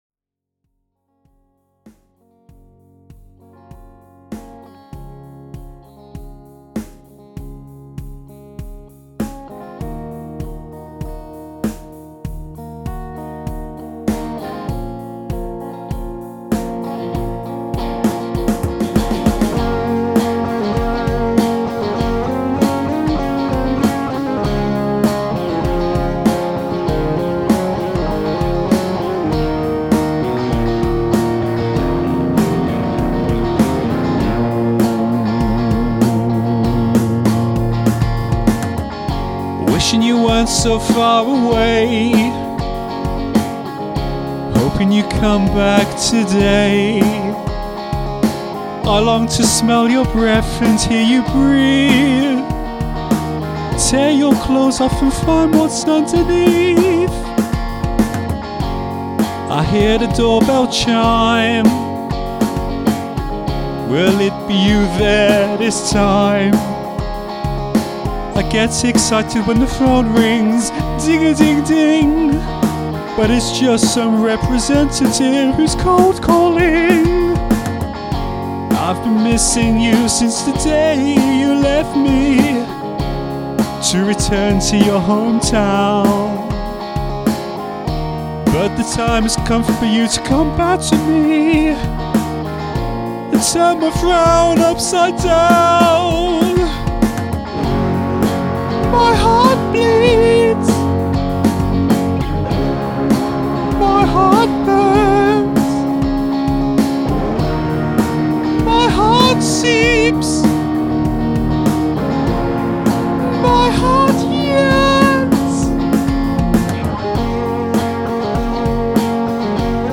power ballad
melancholic melody and blazing rock guitar